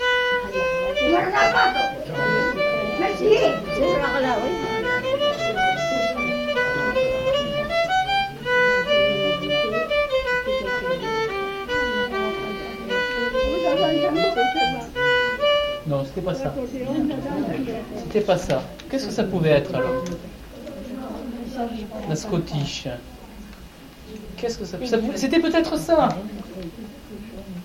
Aire culturelle : Couserans
Lieu : Castillon-en-Couserans
Genre : morceau instrumental
Instrument de musique : violon
Danse : scottish